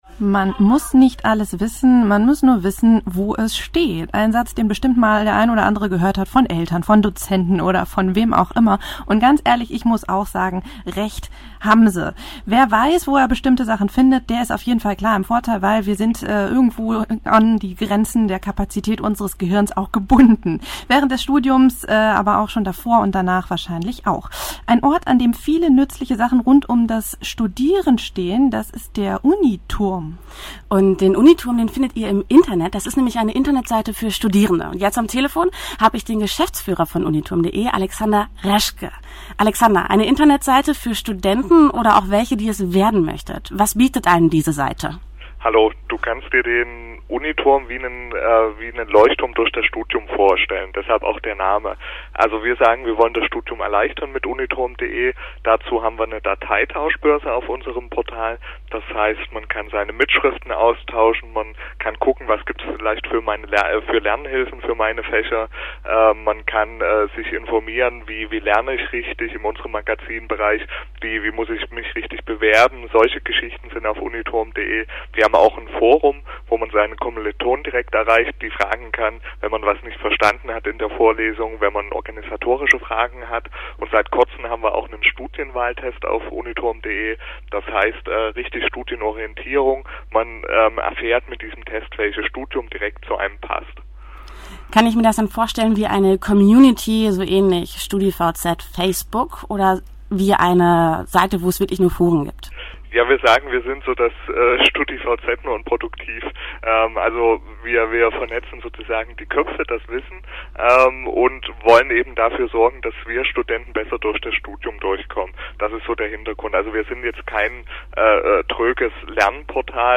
Radio-Interview zum Studienwahltest auf Radio Hertz 87,9